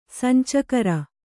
♪ sancakara